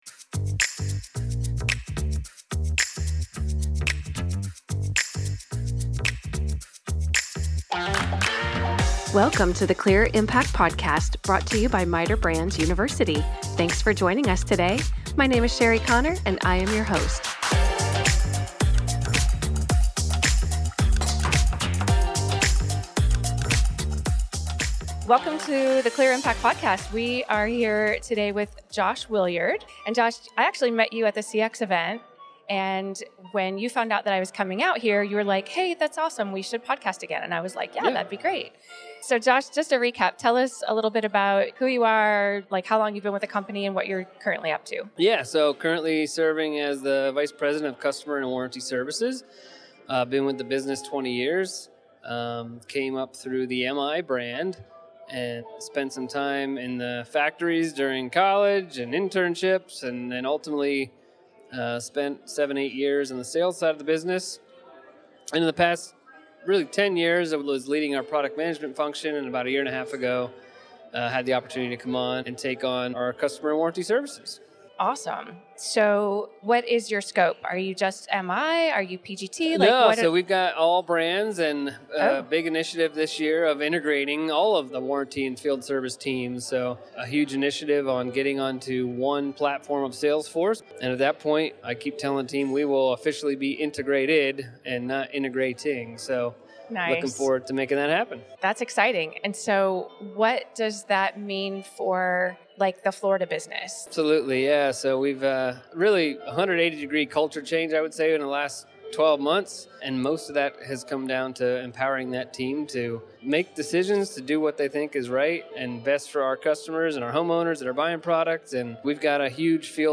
We also chat briefly about the MITER Foundation Golf Event in Hershey, where this episode was recorded.